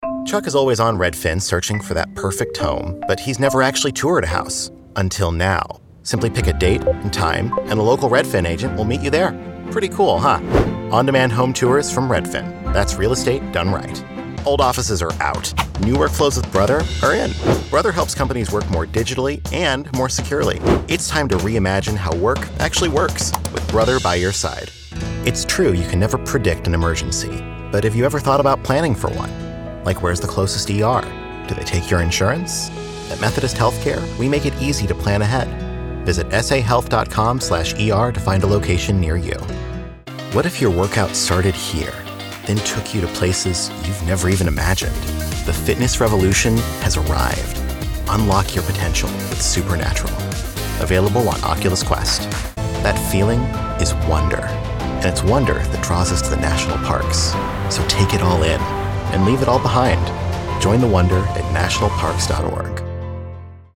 a warm and knowledgable millennial
Commercial